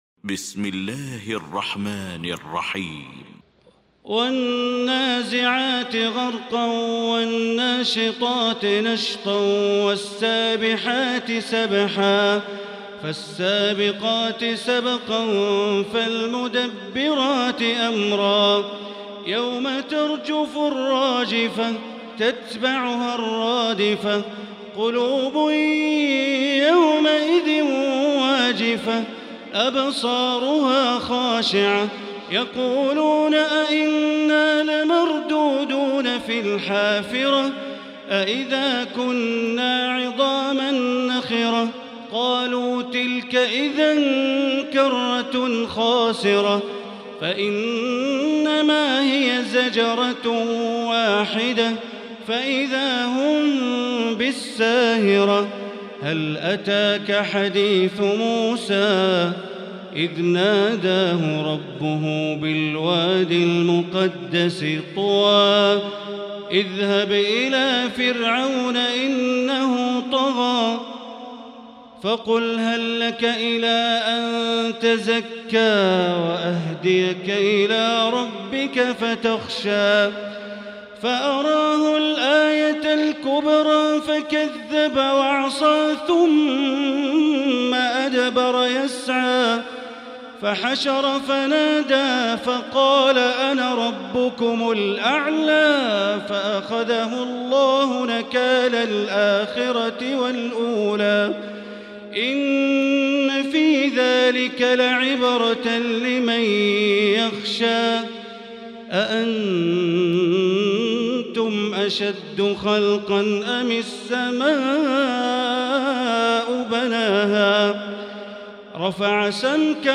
المكان: المسجد الحرام الشيخ: معالي الشيخ أ.د. بندر بليلة معالي الشيخ أ.د. بندر بليلة النازعات